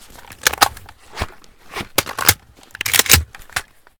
vihr_reload_empty.ogg